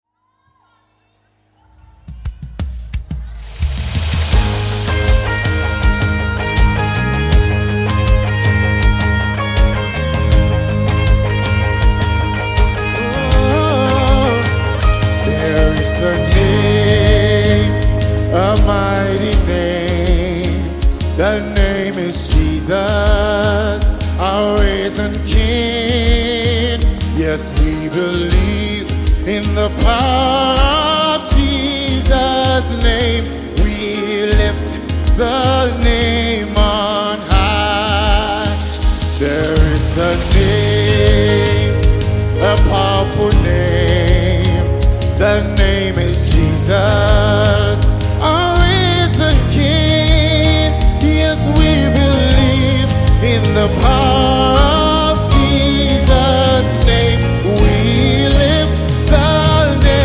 Home » Gospel
Highly gifted vocalist